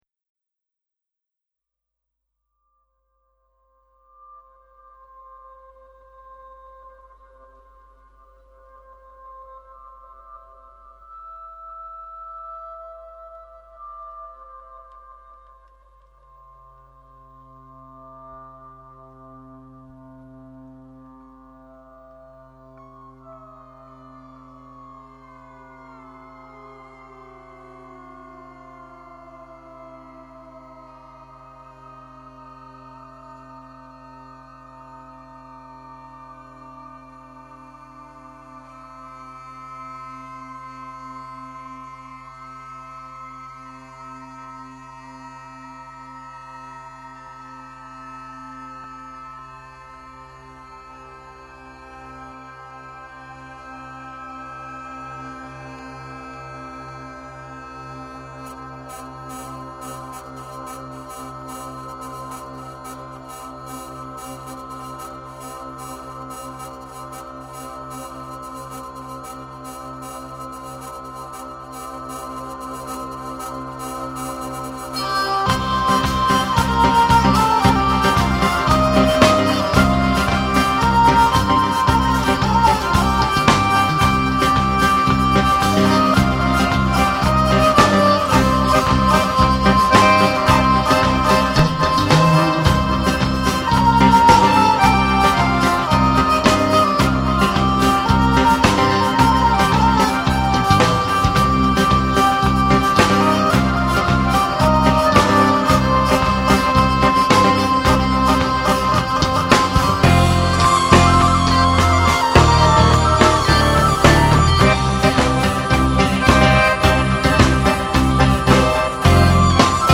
Grabado en concierto